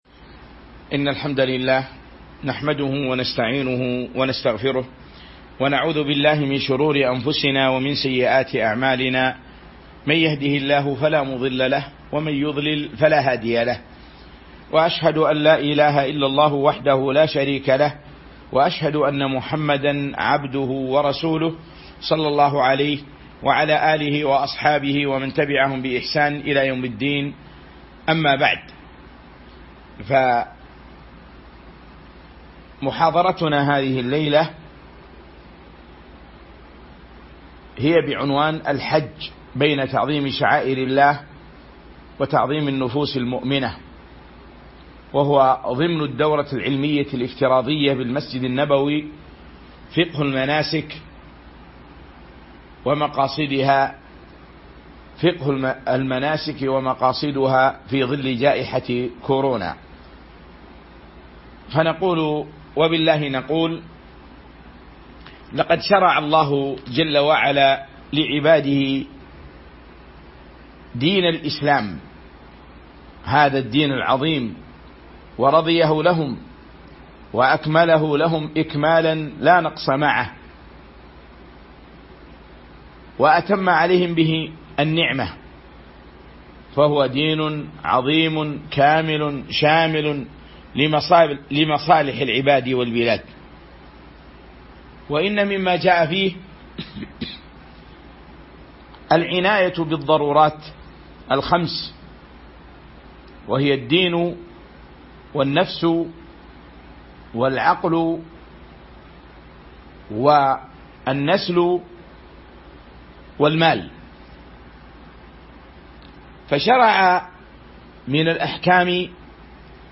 تاريخ النشر ٧ ذو الحجة ١٤٤١ هـ المكان: المسجد النبوي الشيخ